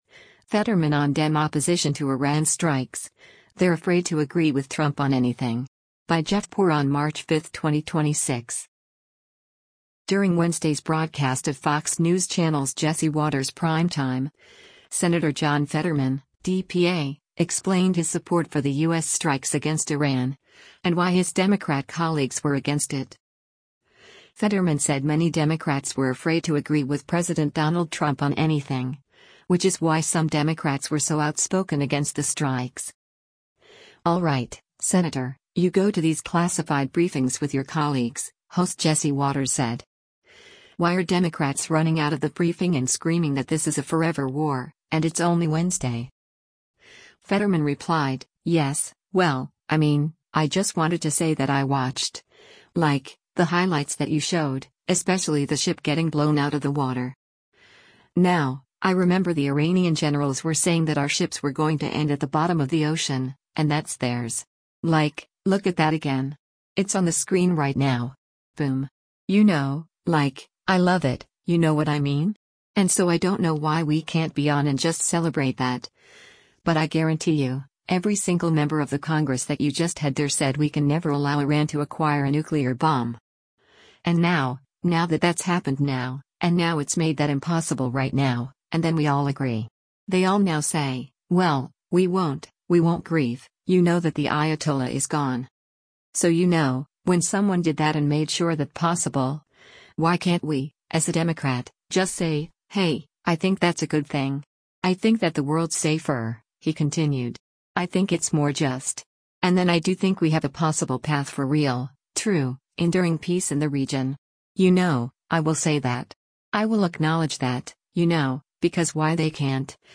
During Wednesday’s broadcast of Fox News Channel’s “Jesse Watters Primetime,” Sen. John Fetterman (D-PA) explained his support for the U.S. strikes against Iran, and why his Democrat colleagues were against it.